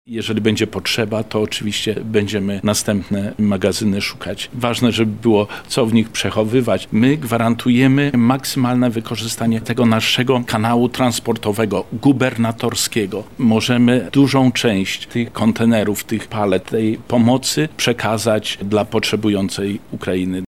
• mówi wicemarszałek województwa lubelskiego Zbigniew Wojciechowski.